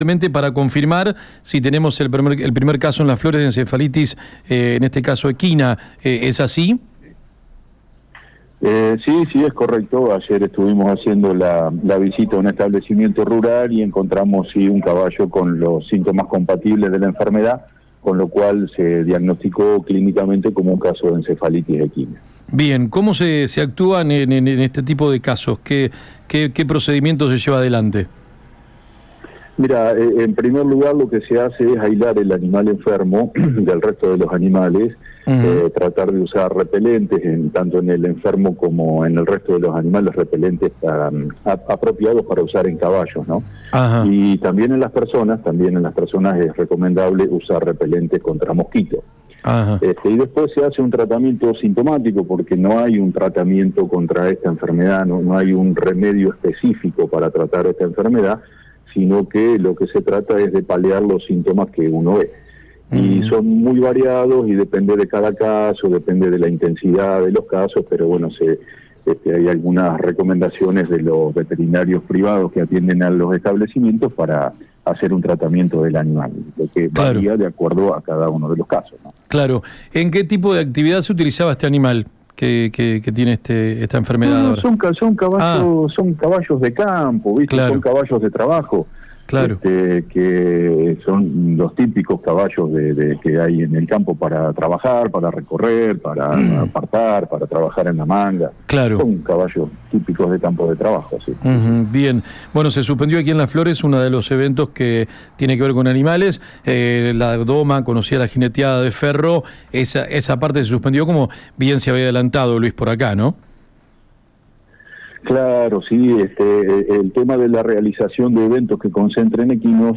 En una comunicación con Urbana FM 92.7